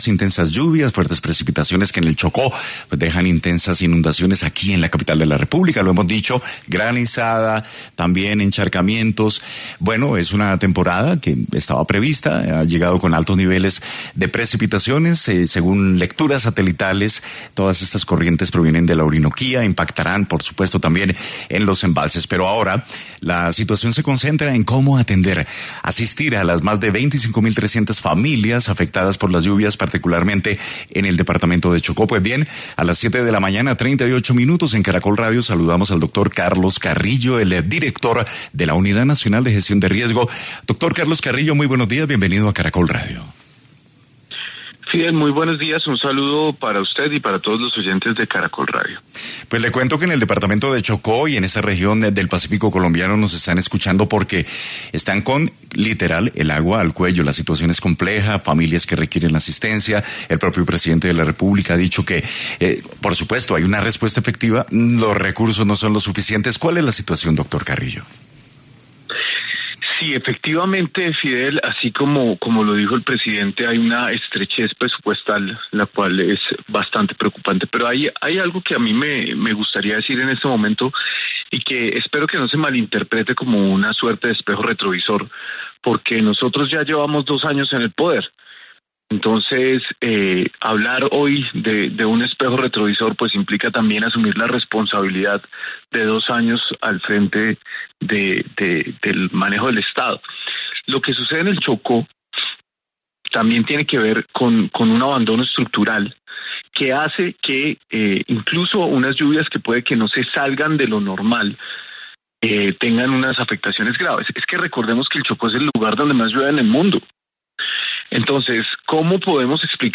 Carlos Carrillo, director de la Unidad de Gestión del Riesgo, estuvo en Caracol Radio hablando sobre las medidas que se tomarán por las fuentes lluvias que se están presentando en el Chocó.